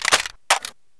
Index of /server/sound/weapons/cw_kk_hk416